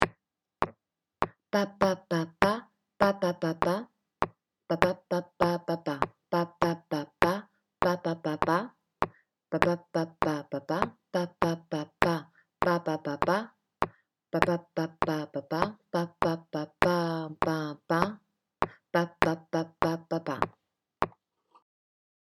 Rythmique à travailler refrain :
comme-ci-comme-c3a7a-papa-refrain.mp3